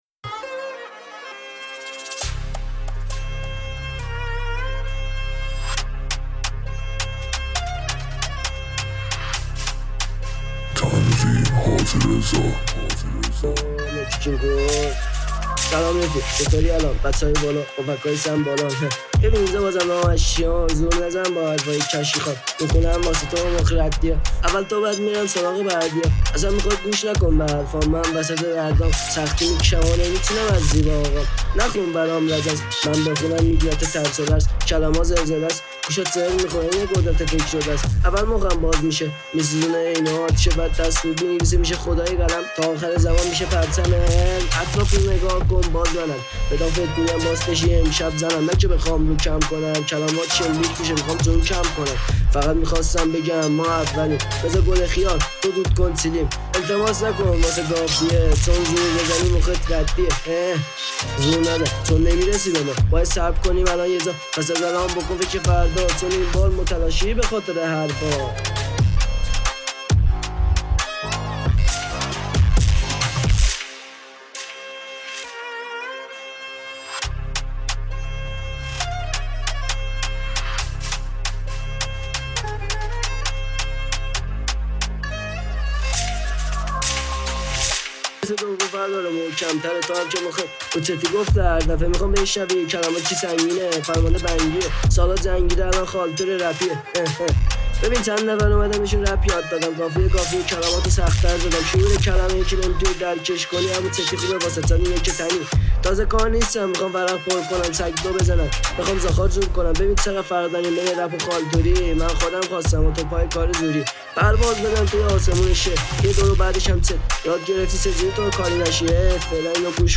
تهیه شده در(استدیو رکورد)
دریل
رپ